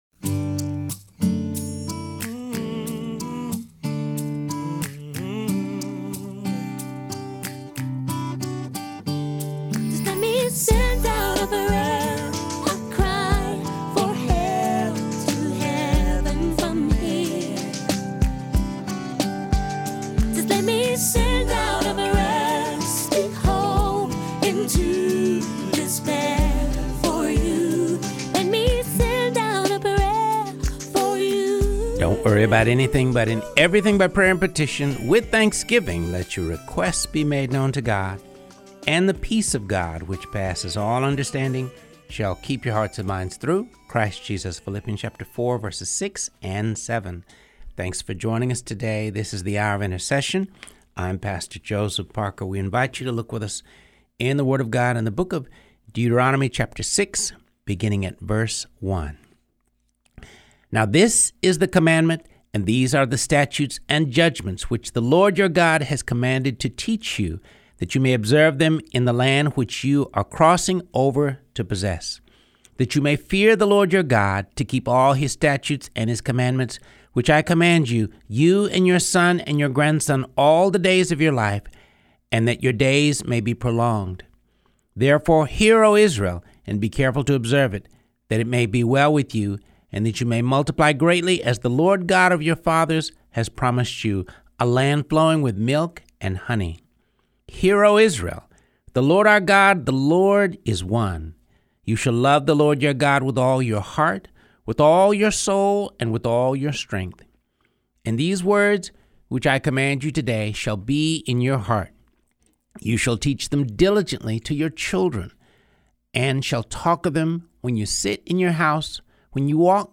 Along the way, you'll hear a half dozen speeches from the 10 Commandments Project.